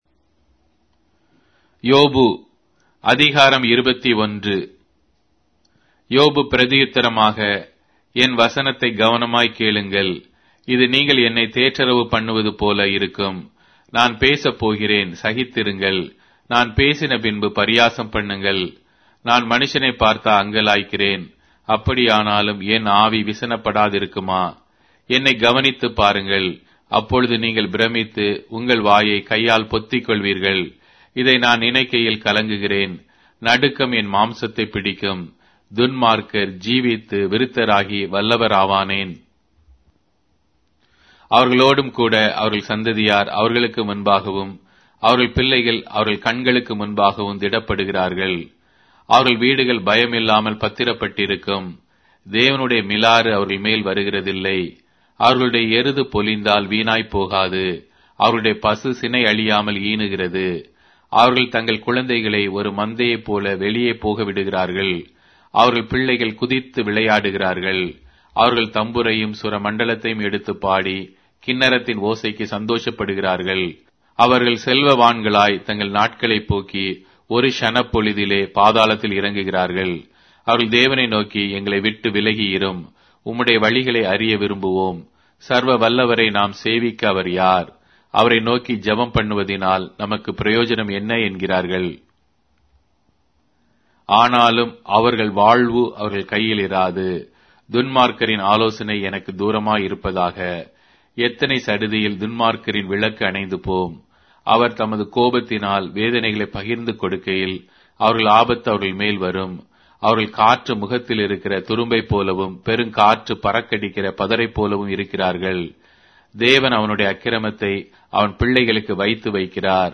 Tamil Audio Bible - Job 15 in Nlt bible version